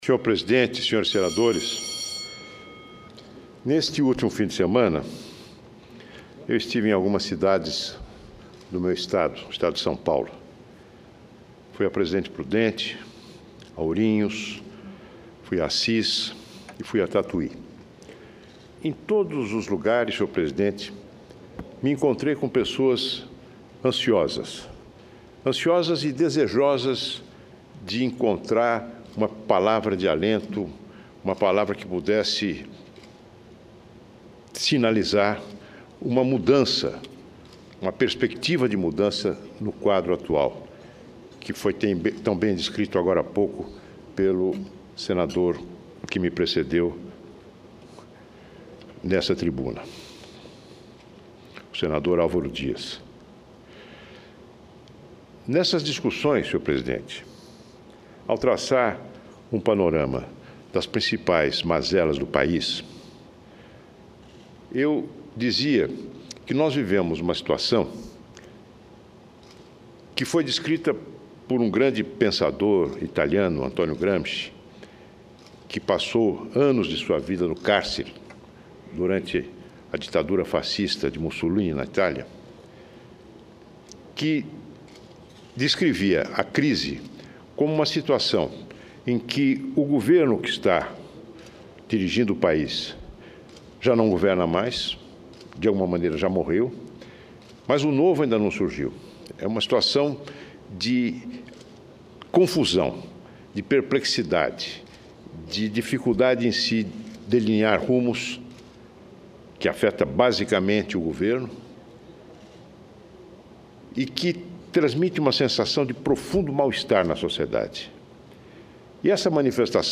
Discursos
Plenário do Senado Federal durante sessão não deliberativa ordinária. Em discurso, senador Aloysio Nunes (PSDB-SP).